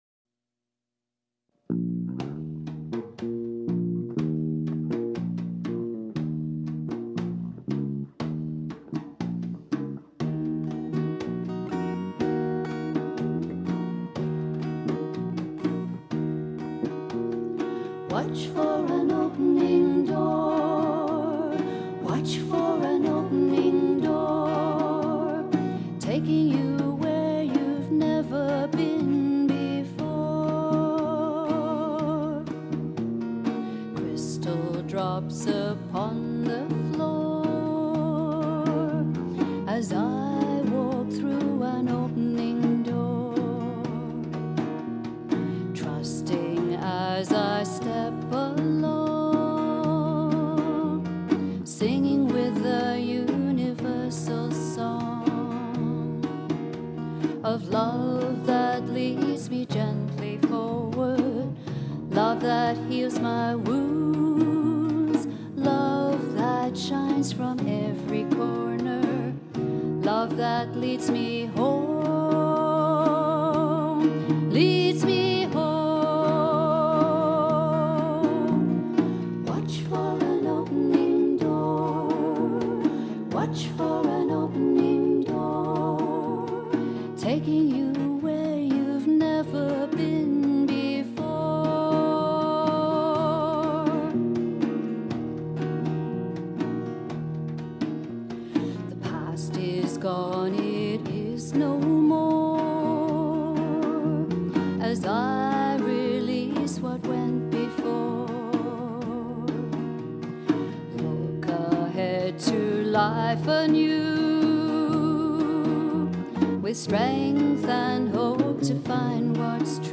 Bass
Percussion
Recorded "Live"
It was like recording in the intimacy of a living room.